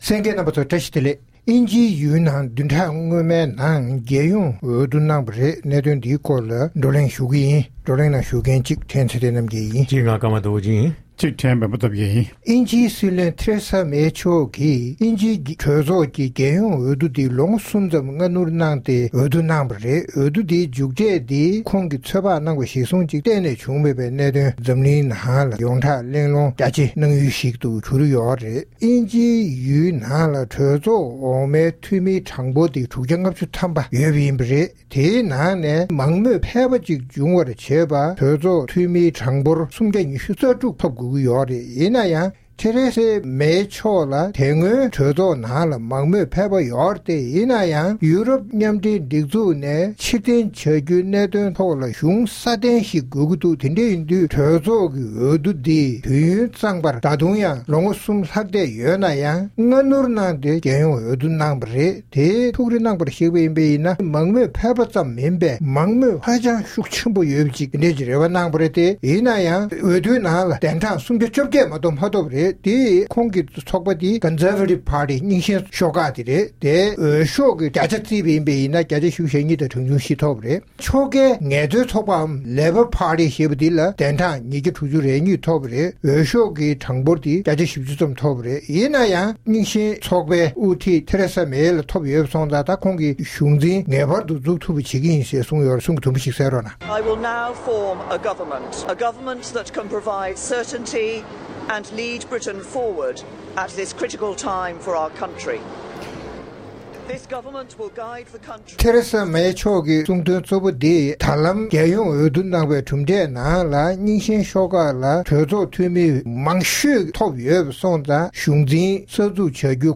༄༅༎རྩོམ་སྒྲིག་པའི་གླེང་སྟེགས་ཞེས་པའི་ལེ་ཚན་ནང་། ཉེ་ལམ་དབྱིན་ཡུལ་དུ་གཞུང་འཛིན་ཚོགས་པས་རྒྱལ་ཡོངས་གྲོས་ཚོགས་ཀྱི་འོས་བསྡུ་ལོ་ངོ་གསུམ་ཙམ་སྔ་བསྣུར་གནང་སྟེ་གནང་ཡང་དམིགས་ཡུལ་མ་འགྲུབ་པའི་རྒྱུ་རྐྱེན་དང་། འབྱུང་འགྱུར་མཇུག་འབྲས་ཇི་ཡོང་སོགས་ཀྱི་སྐོར་རྩོམ་སྒྲིག་འགན་འཛིན་རྣམས་ཀྱིས་བགྲོ་གླེང་གནང་བ་ཞིག་གསན་རོགས་གནང་།།